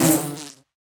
sounds / mob / bee / hurt2.ogg